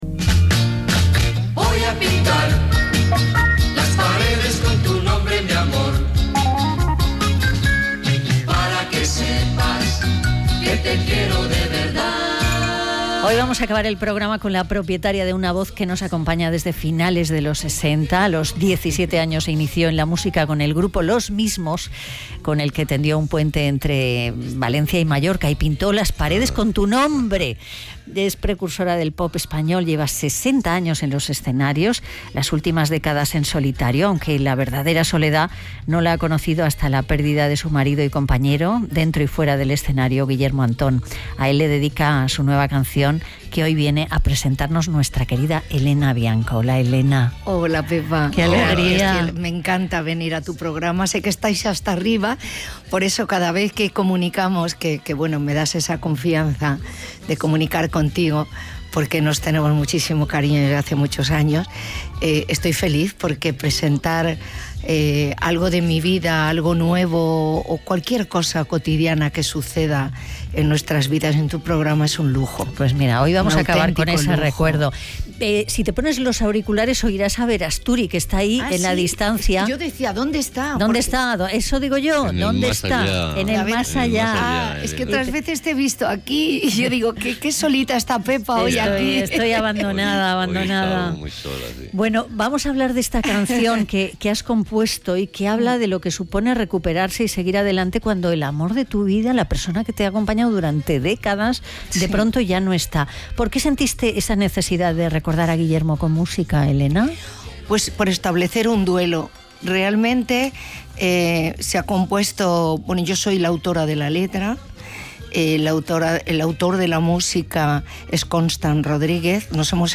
Entrevista RNE «No es un día cualquiera»
entrvista-rne.mp3